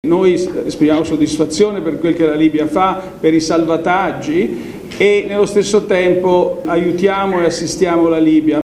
Nell’incontro si è parlato dei rapporti economici tra i due paesi, ma anche di migranti, un tema che Draghi ha toccato davanti ai giornalisti:
draghi-19.30-libia.mp3